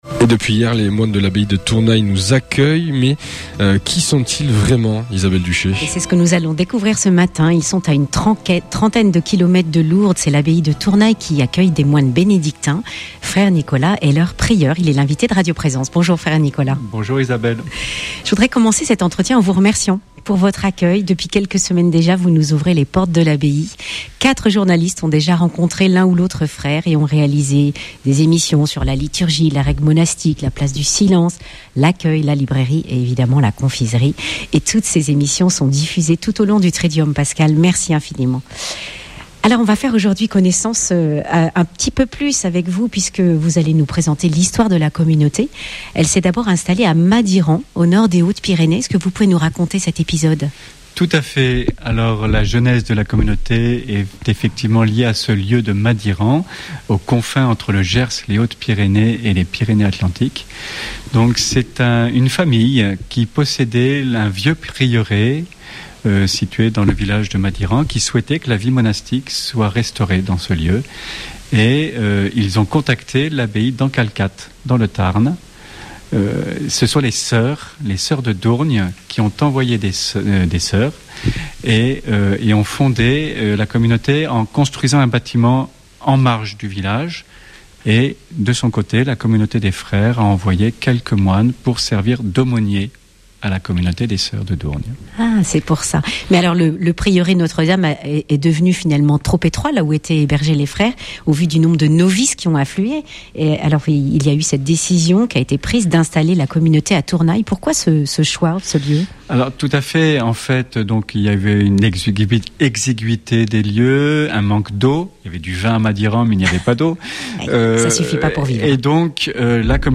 Accueil \ Emissions \ Information \ Régionale \ Le grand entretien \ Quelle est l’histoire de la communauté des Bénédictins de Tournay (...)